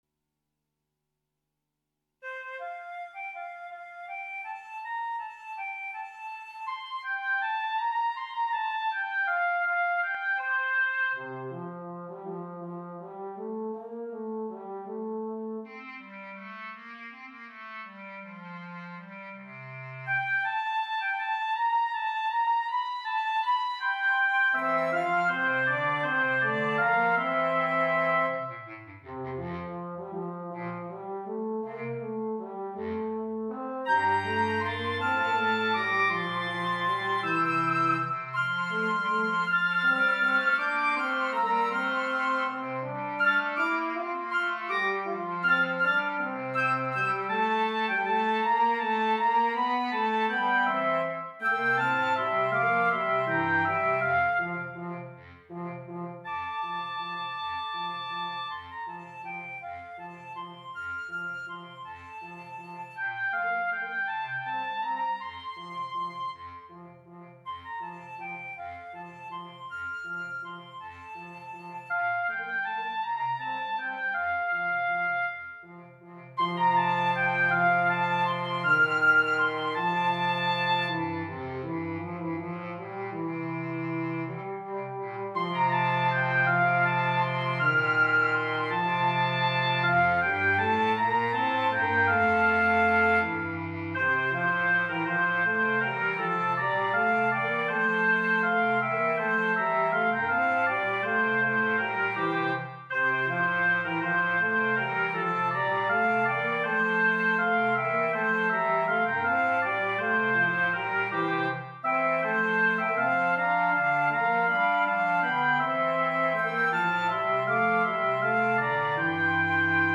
Instrumentation: C, Bb, Eb
A four part instrumental arrangement
cheerful Christmas Song.